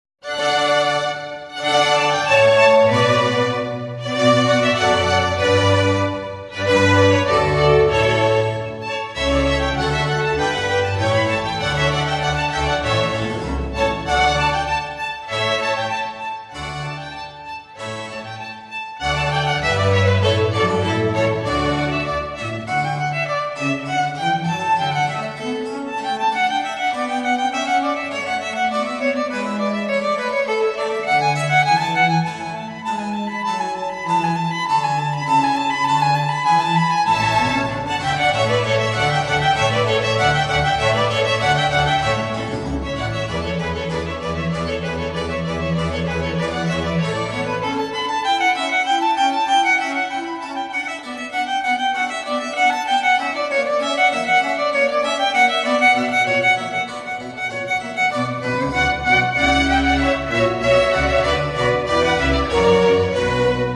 A. Vivaldi Koncert pro housle a orchestr D Dur 1:03 765 KB